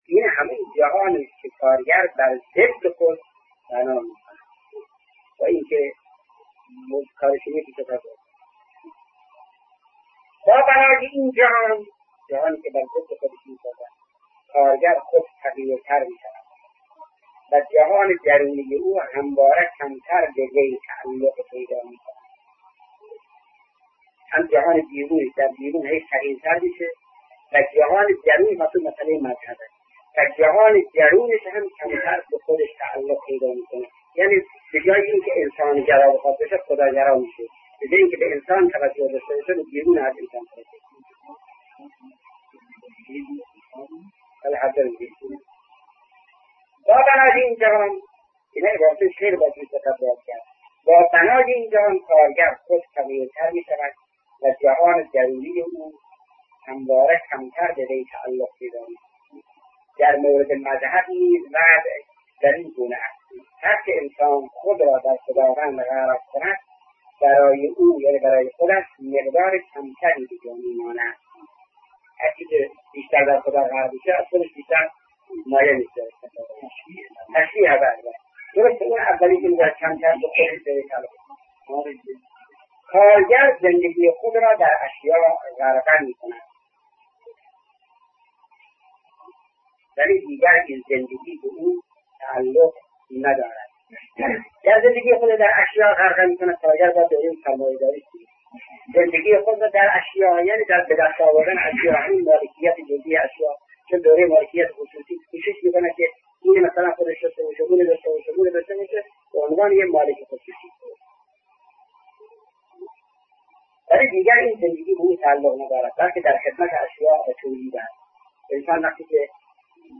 سخنرانی شهید مرتضی مطهری(ره)- با موضوع بیگانگی انسان از خدا- بخش‌چهارم